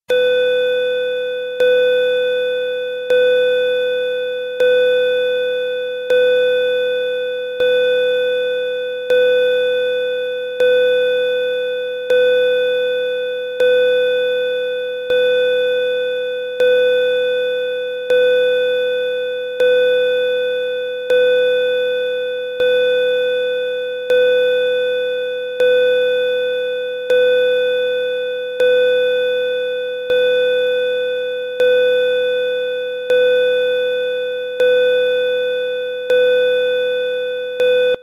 20 Pulse Bell Tone
Antique Bell Car Close Common Dial Ding Door sound effect free sound royalty free Sound Effects